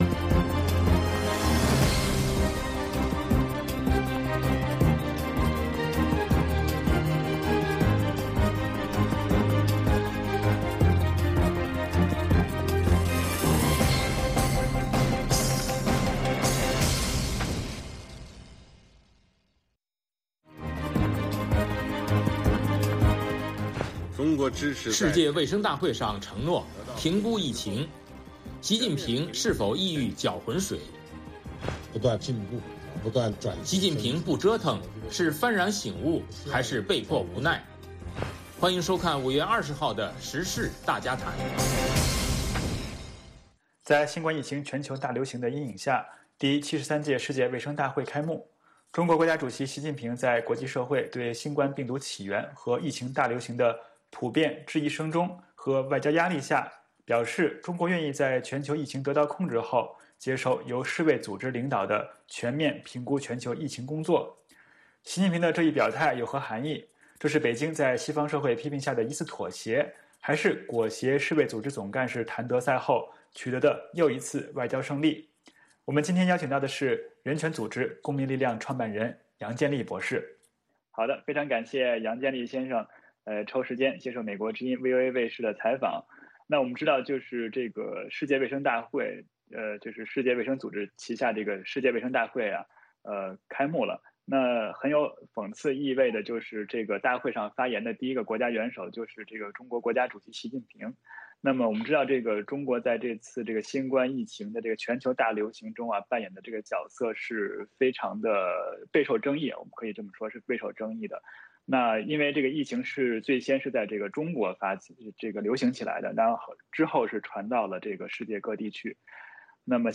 我们邀请中国民主党全国委员会主席王军涛博士来给大家解读。